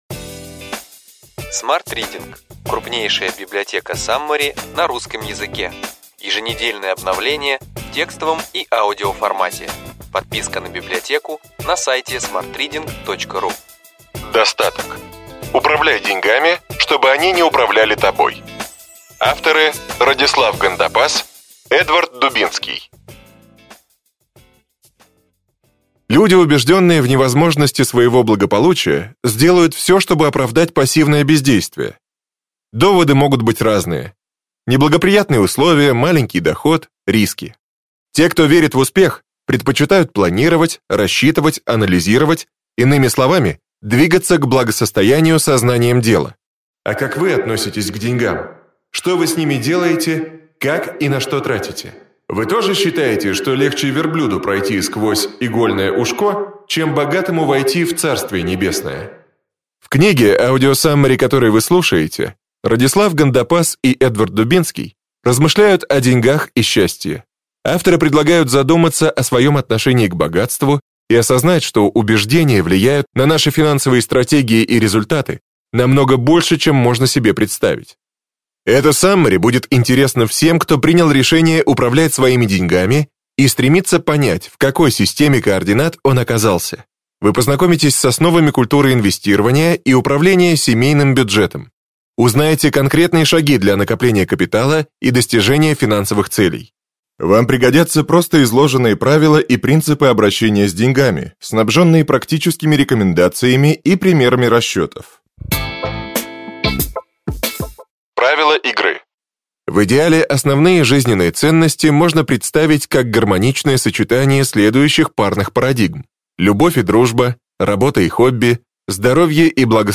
Аудиокнига Ключевые идеи книги: Достаток. Управляй деньгами, чтобы они не управляли тобой.